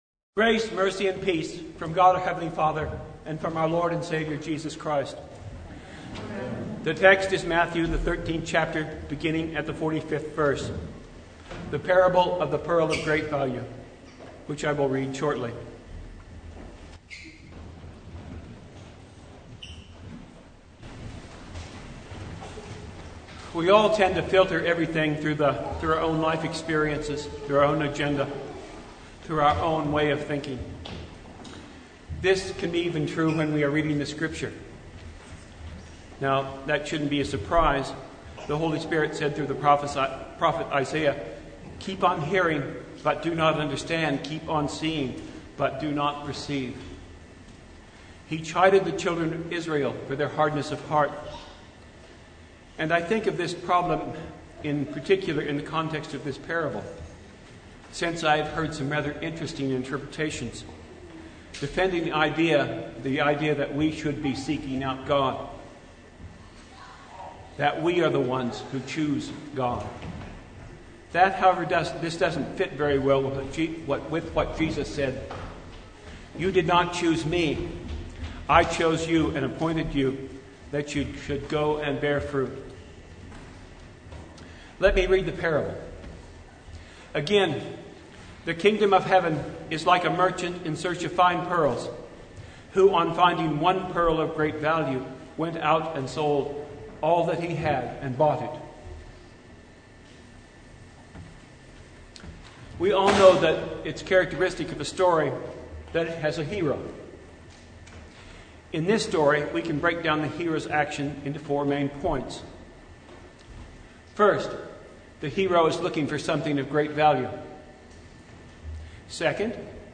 Sermon from Fifth Sunday in Apostles’ Tide (2023)
Sermon Only